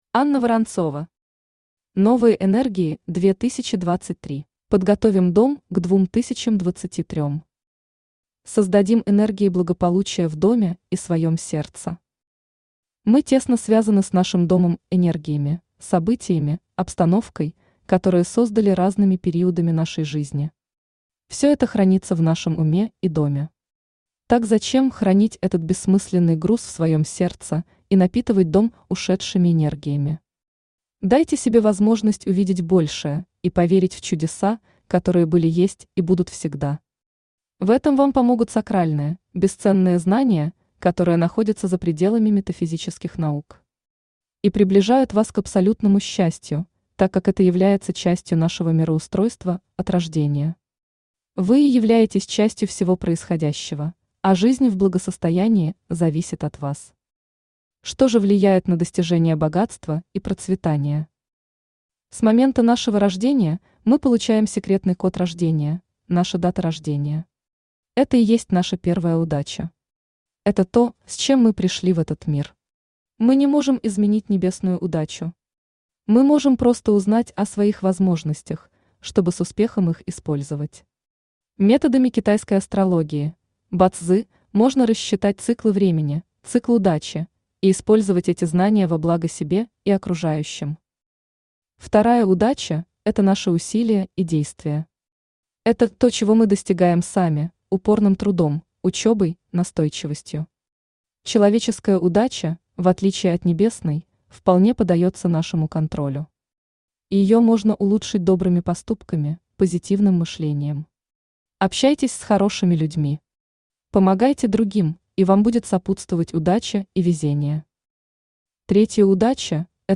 Aудиокнига Новые энергии 2023 Автор Анна Борисовна Воронцова Читает аудиокнигу Авточтец ЛитРес.